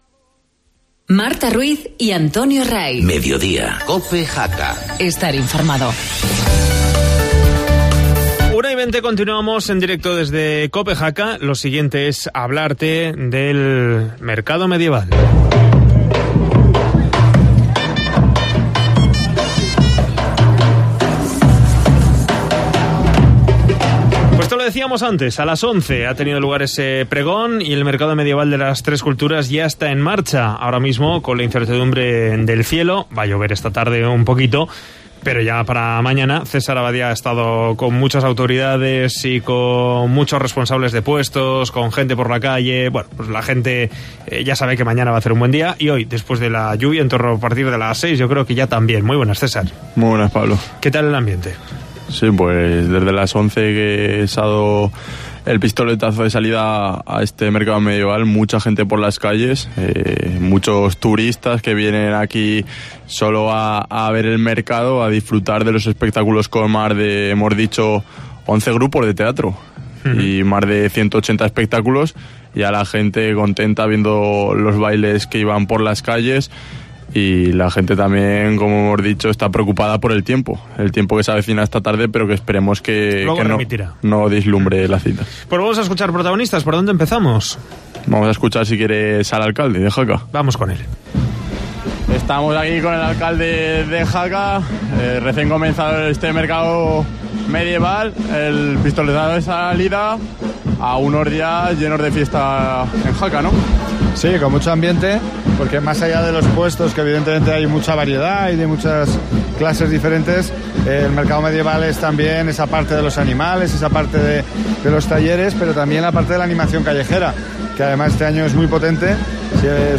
COPE en el Mercado Medieval de Jaca